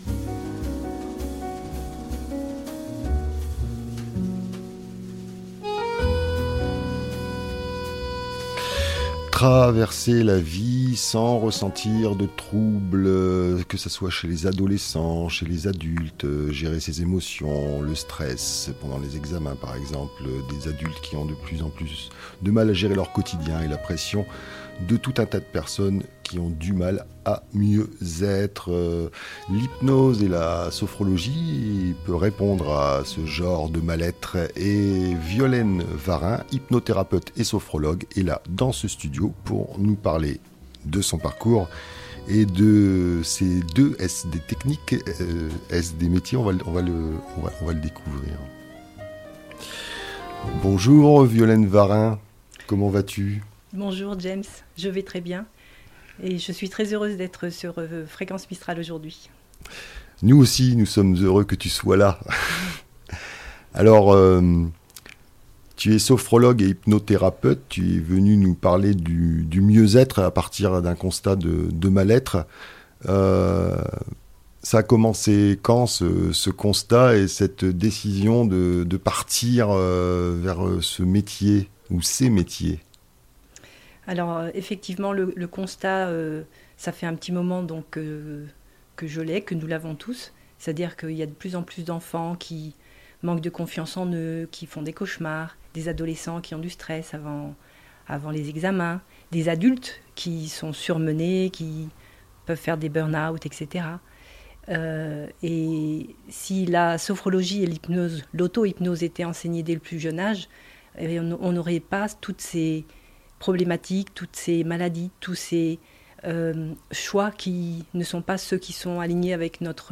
Portrait d'une thérapeute